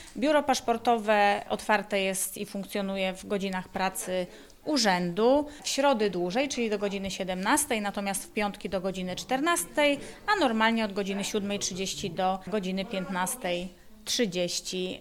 na-strone_2_godziny-dzialania-biura_burmistrz-MiG.mp3